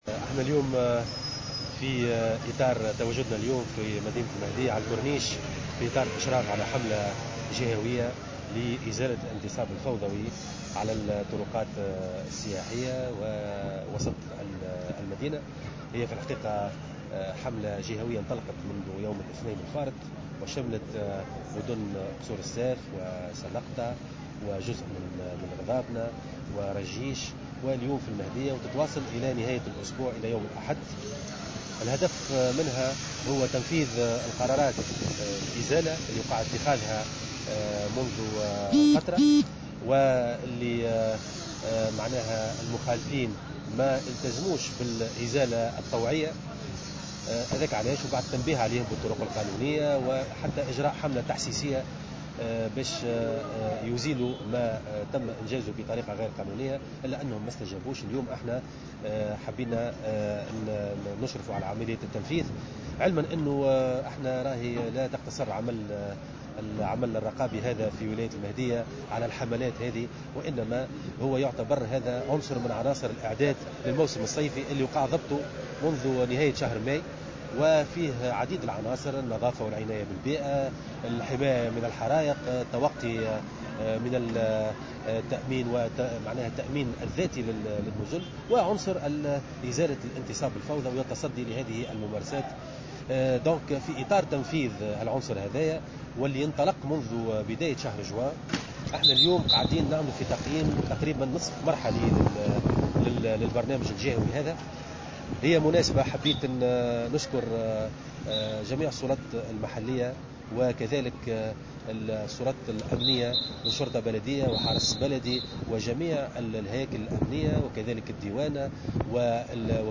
La campagne qui se poursuivra jusqu'à dimanche verra l'exécution de 60 décisions de démolition d'installations anarchiques, a ajouté le gouverneur au micro du correspondant de Jawhara FM.